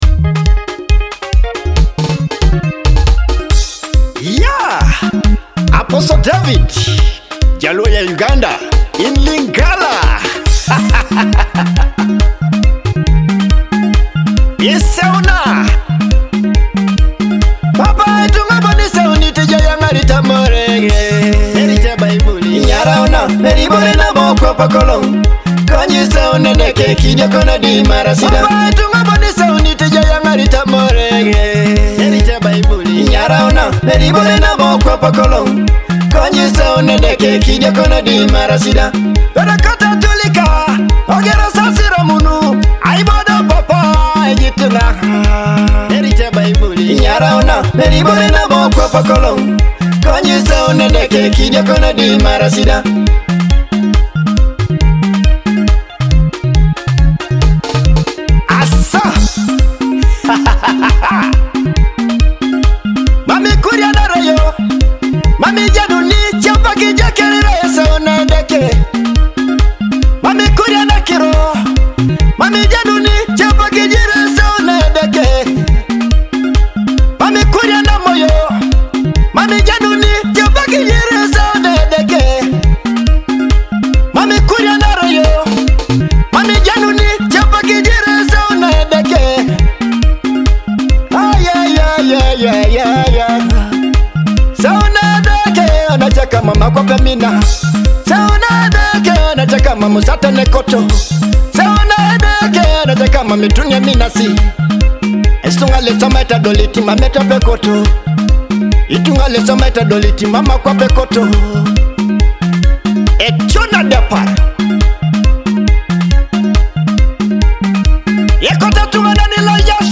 uplifting gospel song blending Jaluo & Lingala